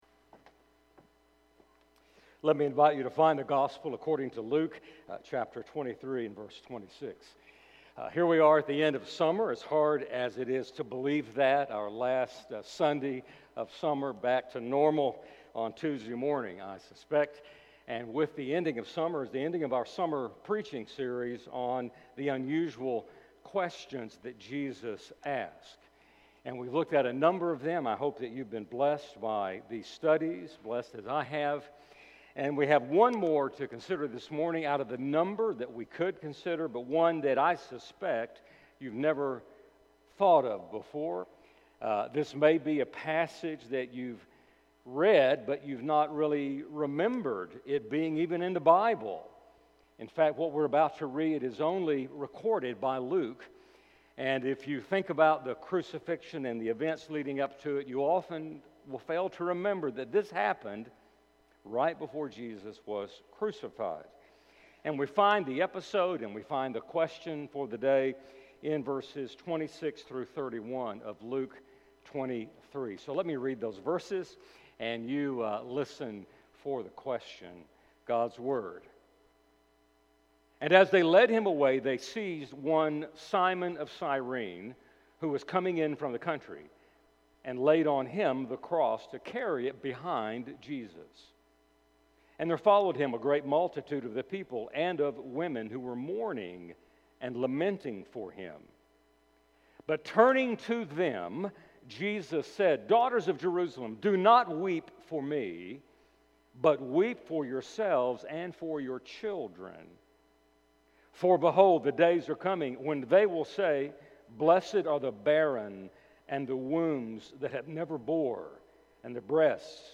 Luke 23:26-31 Service Type: Sunday Morning Sunday’s message will be our final one on ‘The Unusual Questions that Jesus Asked.’